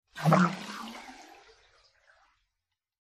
Whoosh
UnderwaterSpearBys PE312101